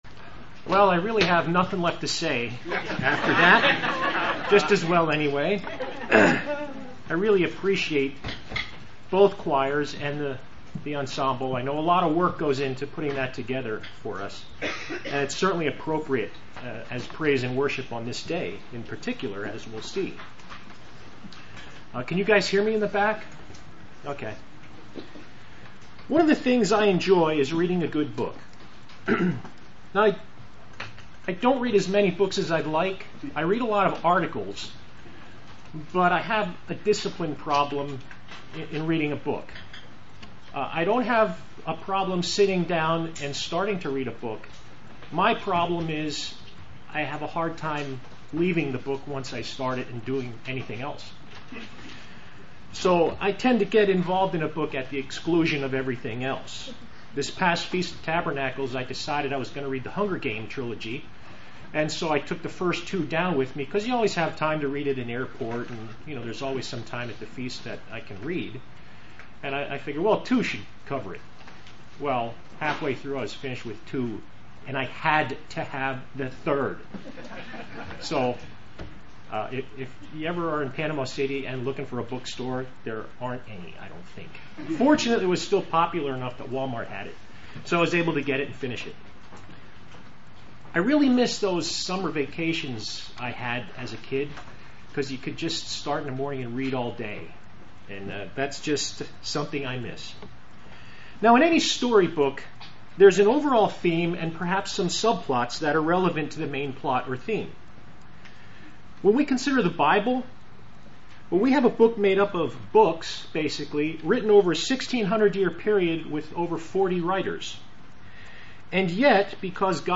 Given in Lehigh Valley, PA
UCG Sermon Studying the bible?